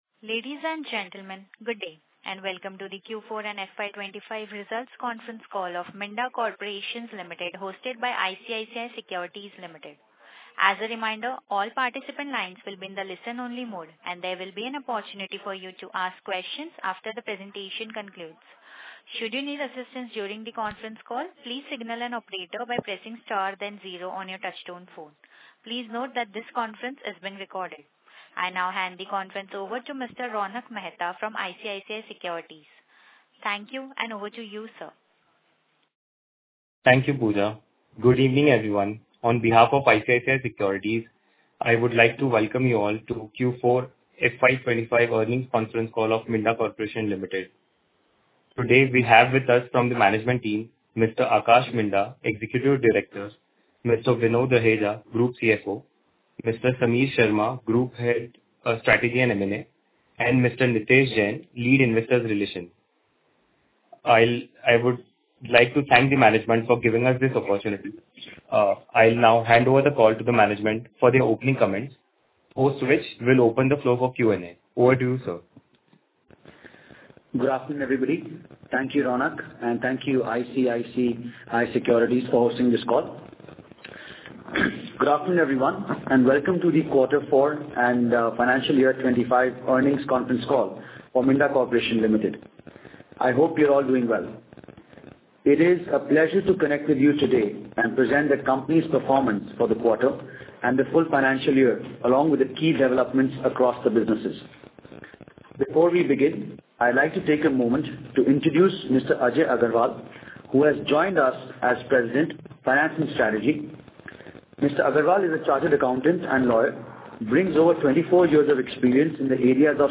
Audio Recording of Conference Call for Business Update